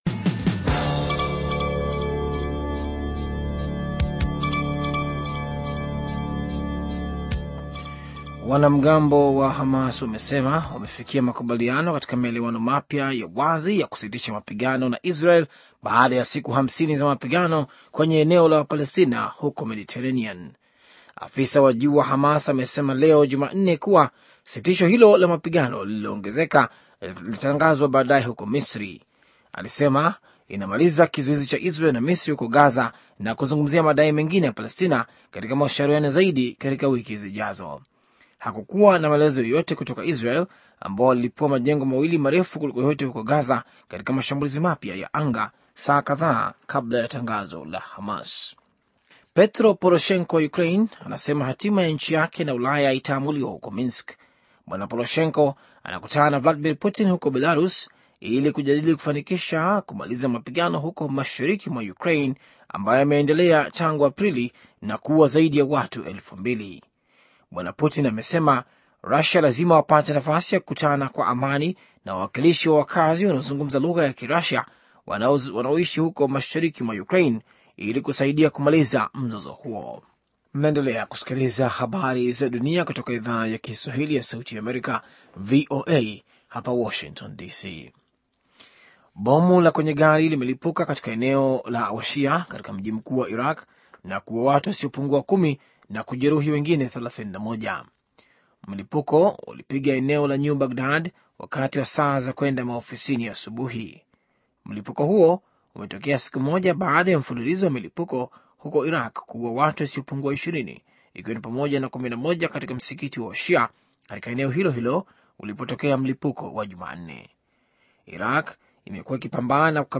Taarifa ya habari - 5:36